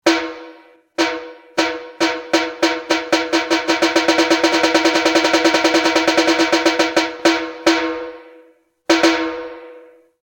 場面転換・オープニング・エンディング
開始の合図２